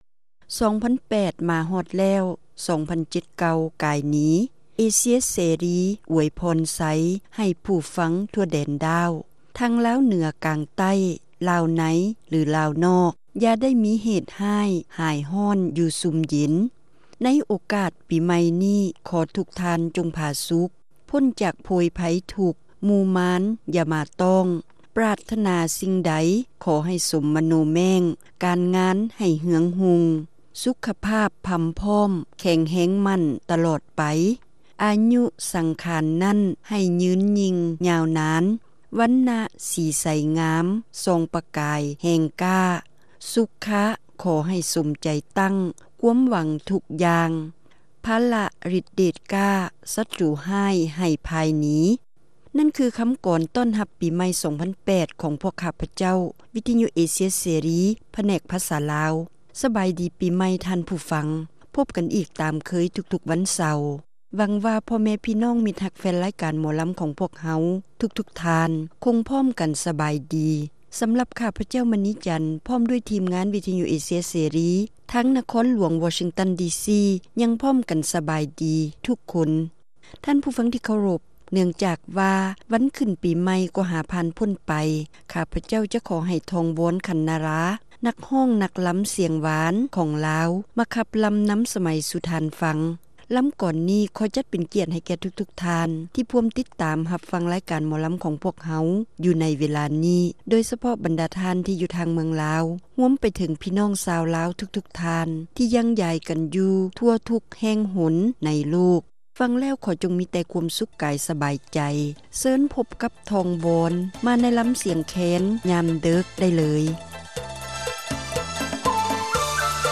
ຣາຍການໜໍລຳ ປະຈຳສັປະດາ ວັນທີ 4 ເດືອນ ມົກະຣາ ປີ 2008